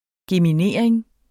Udtale [ gemiˈneˀɐ̯eŋ ]